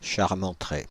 Charmentray (French pronunciation: [ʃaʁmɑ̃tʁɛ]
Fr-Charmentray.ogg.mp3